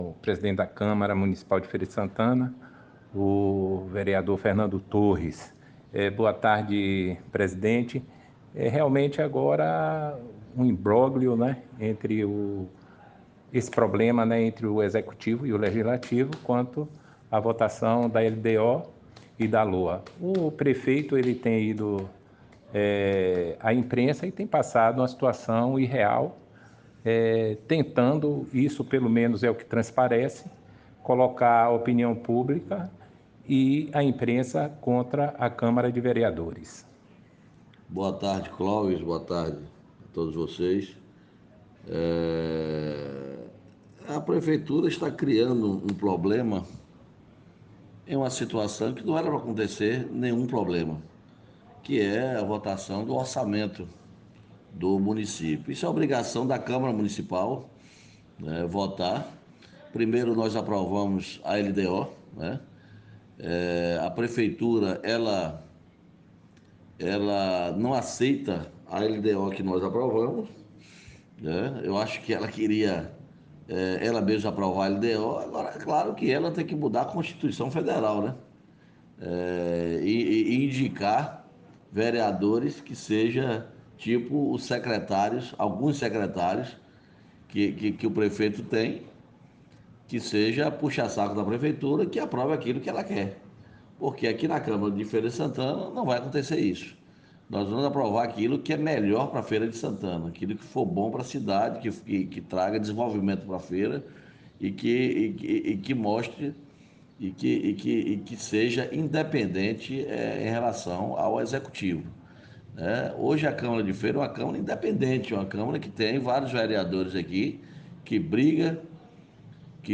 Disse Torres em entrevista